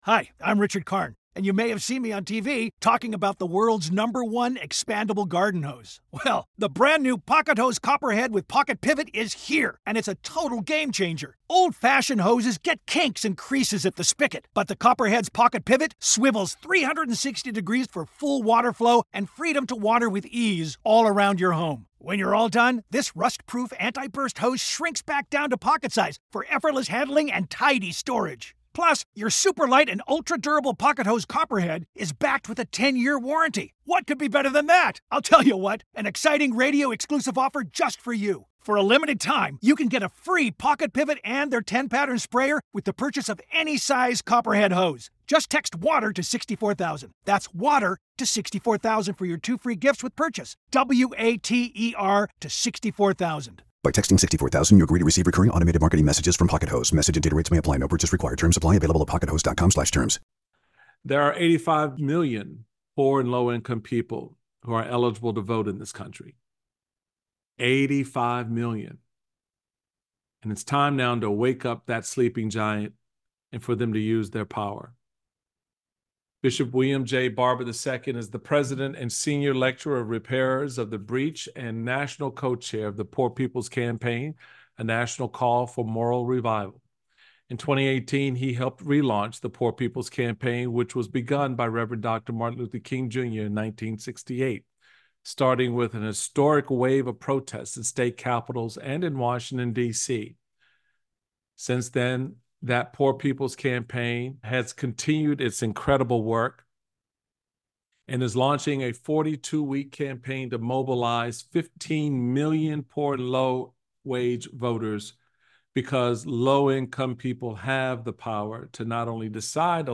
Michael Steele speaks with Bishop William J. Barber II, Co-Chair of the Poor People’s Campaign: A National Call For Moral Revival. The pair discuss the moral failing of poverty— how it's the fourth leading cause of death in America and how mobilizing poor voters could transform elections in America.